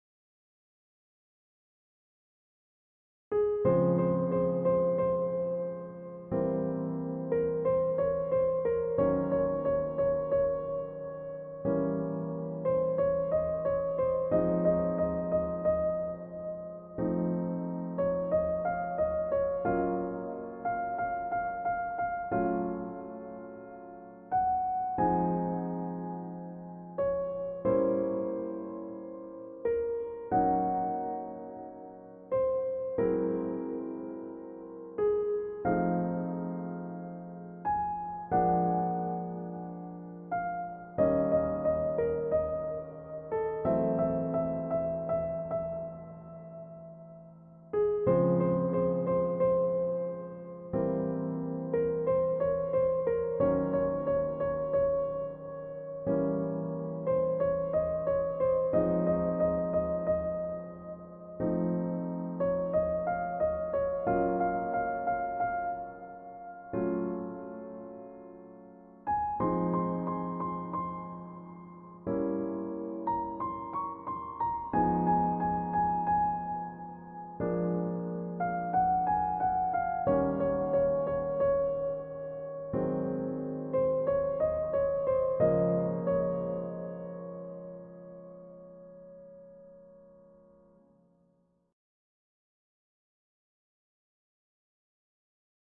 EASY Medium Piano Tutorial